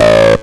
tekTTE63034acid-A.wav